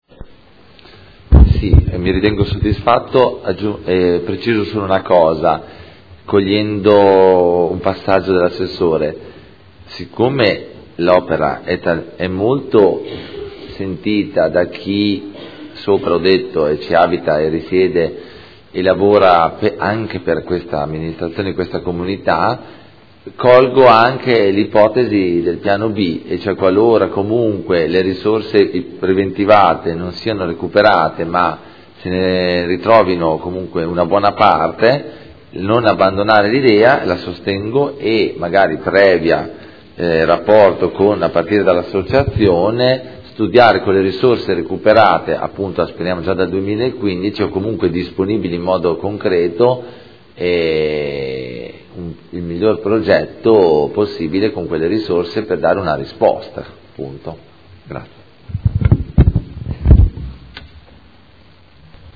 Antonio Carpentieri — Sito Audio Consiglio Comunale